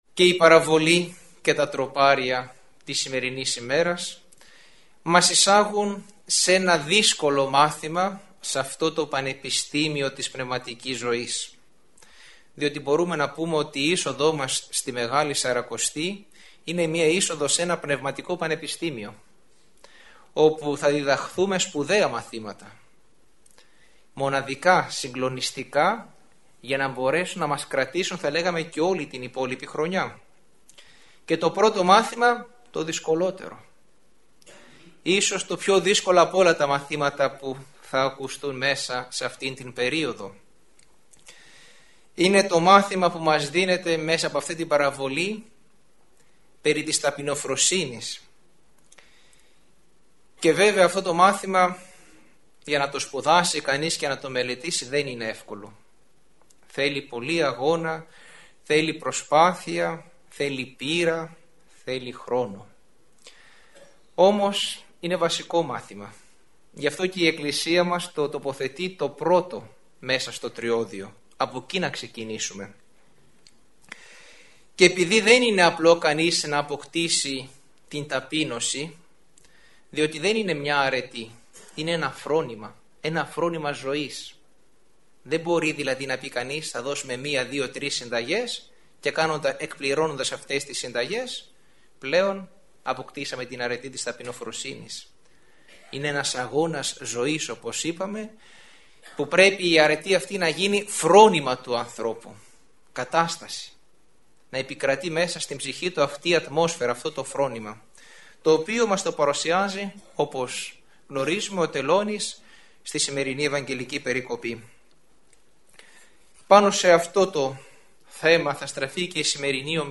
Τελωνικό φρόνημα – ηχογραφημένη ομιλία
Η ομιλία αυτή “έγινε” στην αίθουσα εκδηλώσεων της Χριστιανικής ενώσεως Αγρινίου.